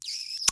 kiss2.wav